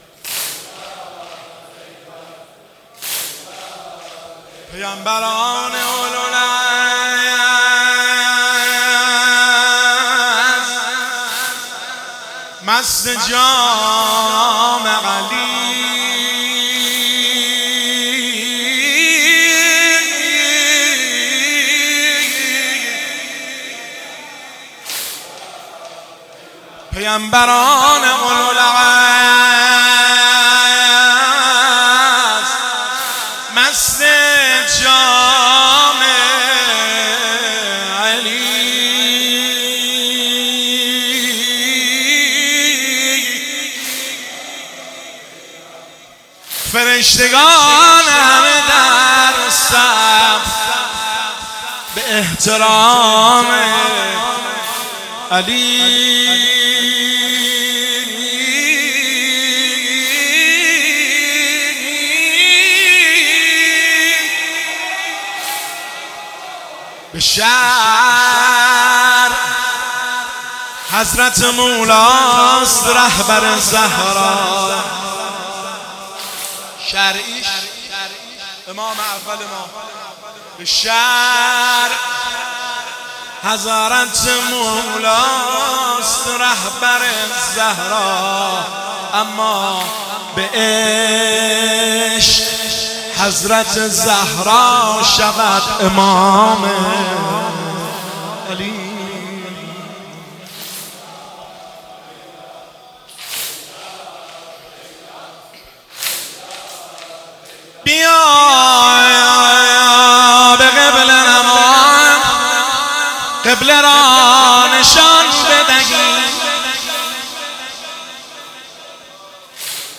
پیامبران-اولوالعزم-شعرخوانی.mp3